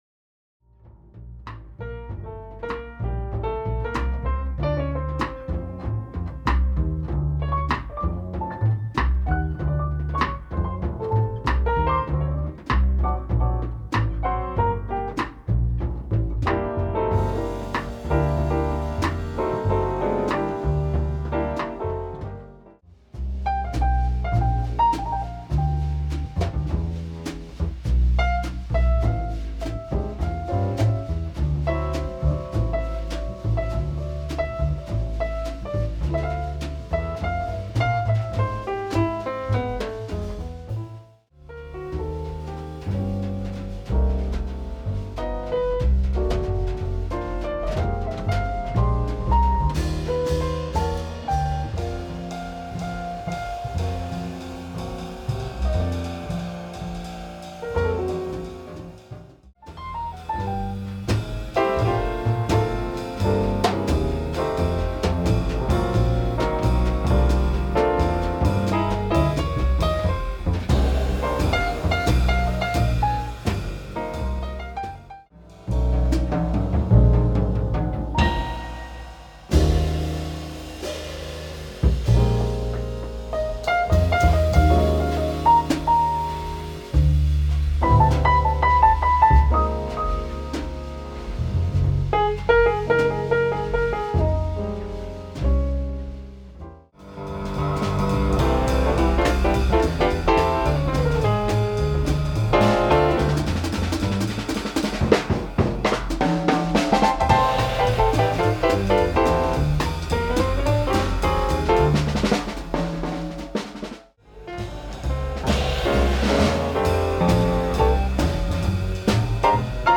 • Repertoire eksempler (Standards & Evergreens)